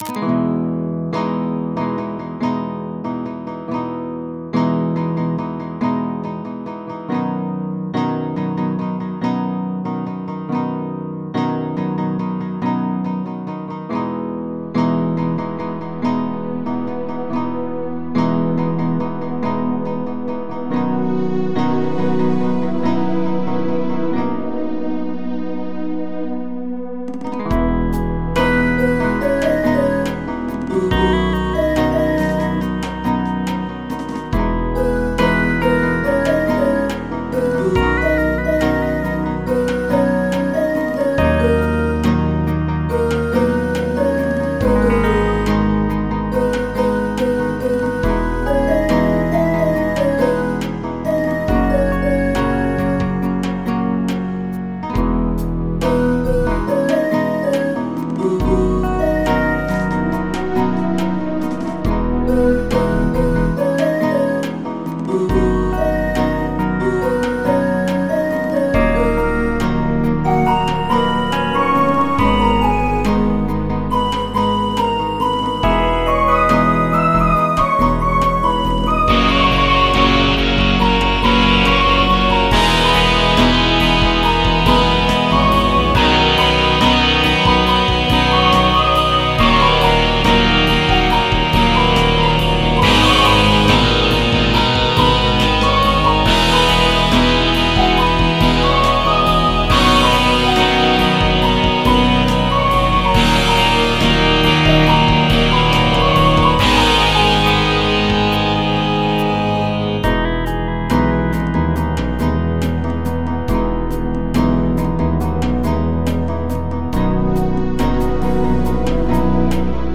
Karaoke Tracks